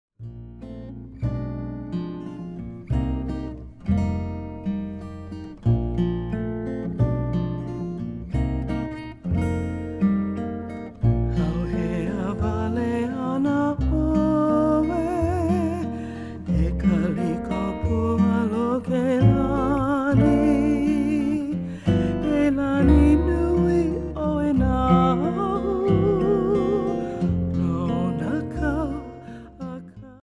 Genre: Contemporary Hawaiian.
slack-key guitar, 'ukulele and vocals